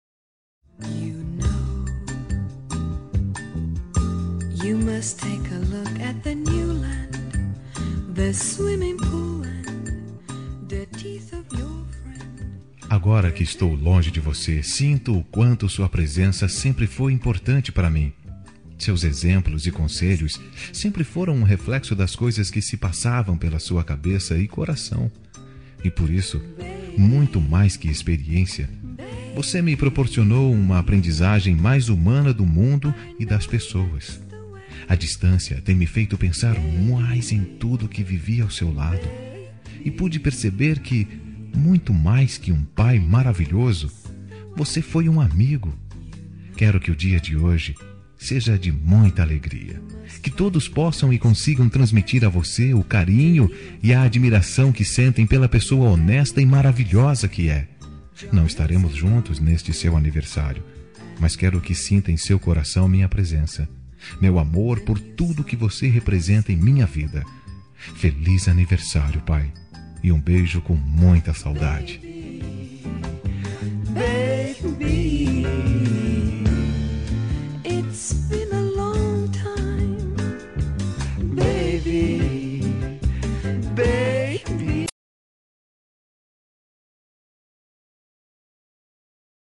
Aniversário de Pai – Voz Masculina – Cód: 11597